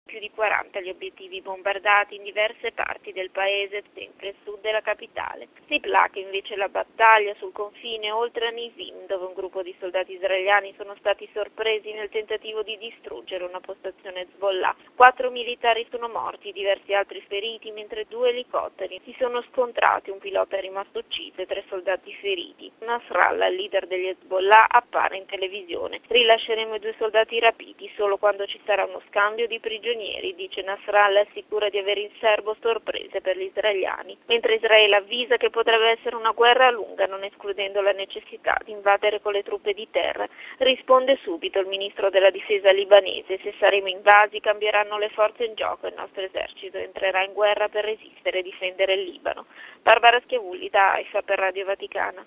Da Haifa